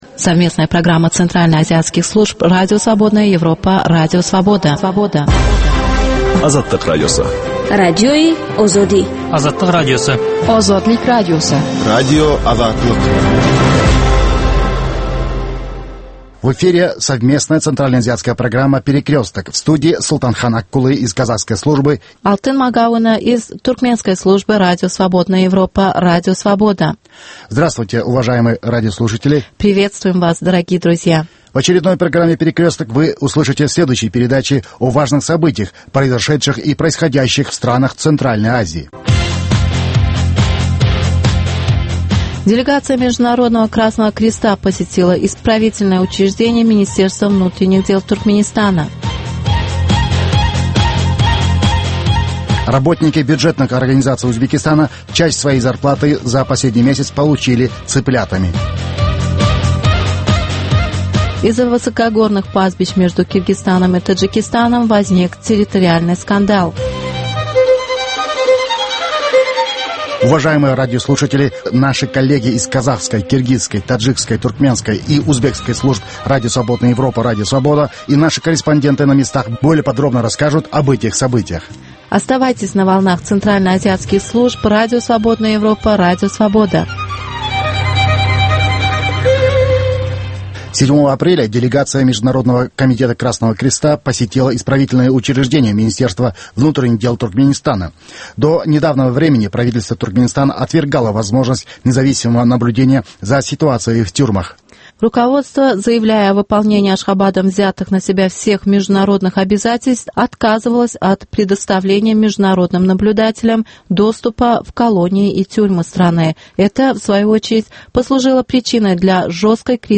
Новости стран Центральной Азии.